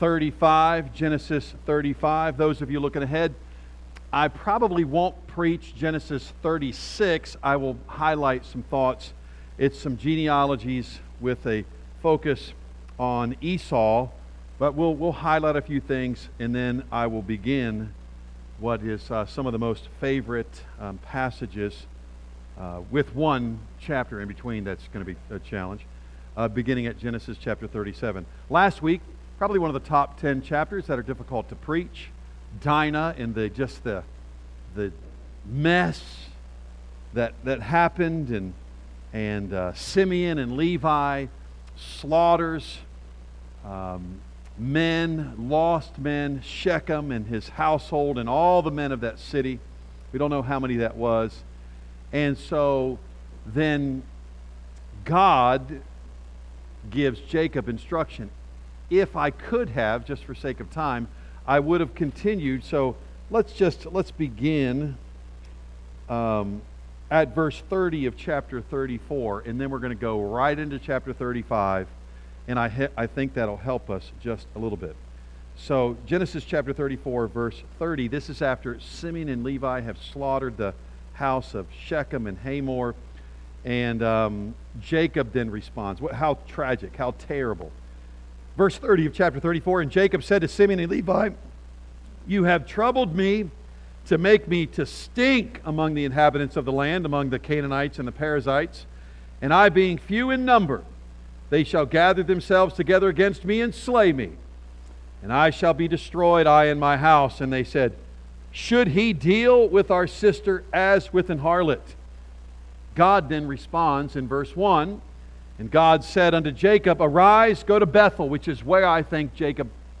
A message from the series "Genesis."